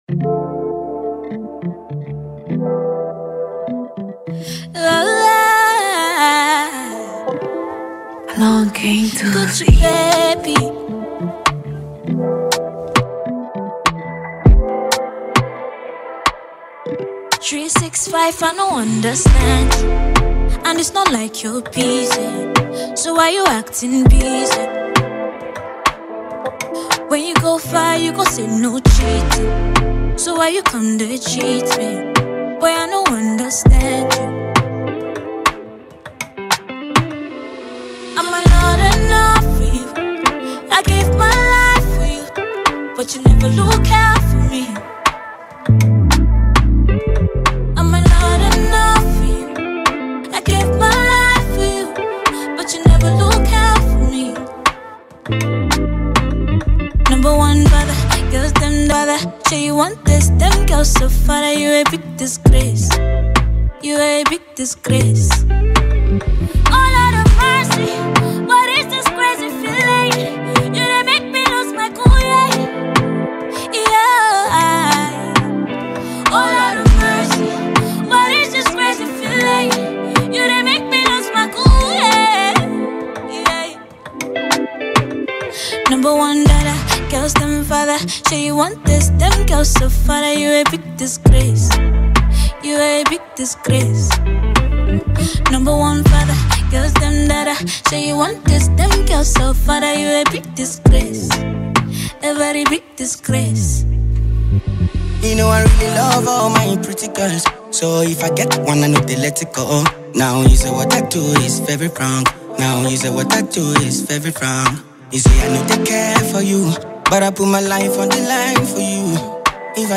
Talented Nigerian guitarist